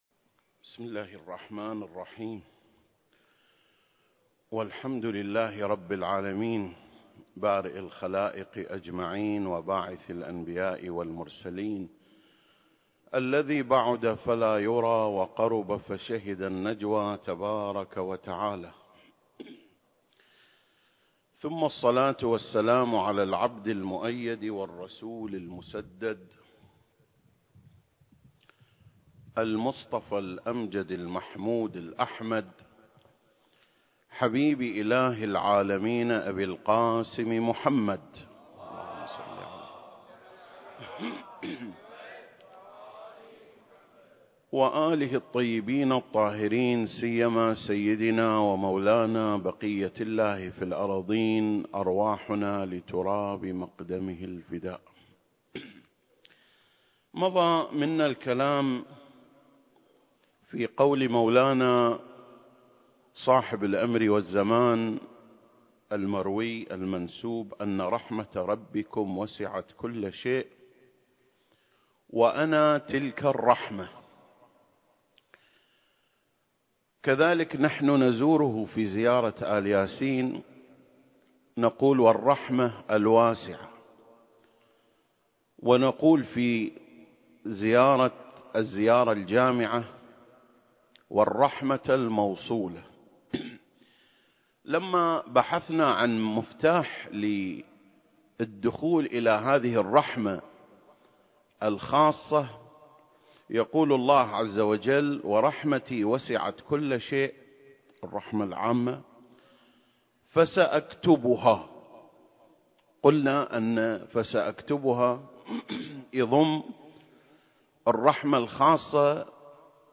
عنوان الخطبة: المهدي (عجّل الله فرجه) رحمة الله الواسعة (3) المكان: مسجد مقامس/ الكويت التاريخ: 2024